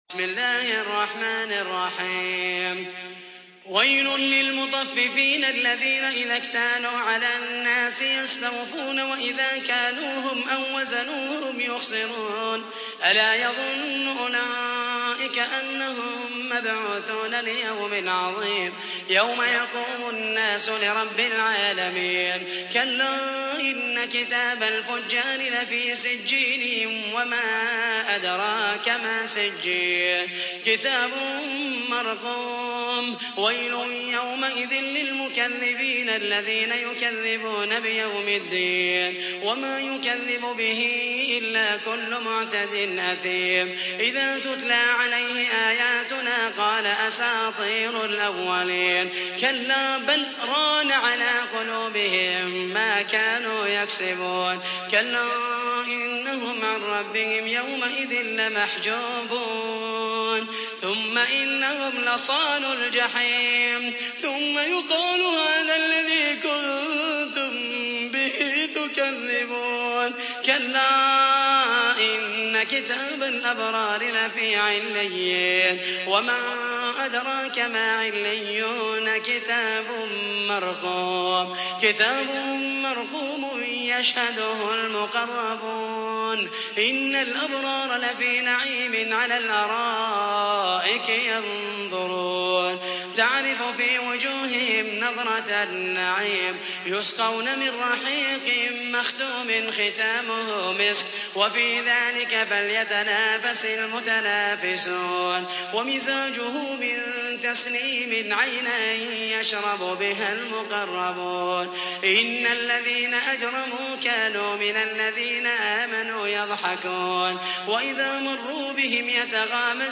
83. سورة المطففين / القارئ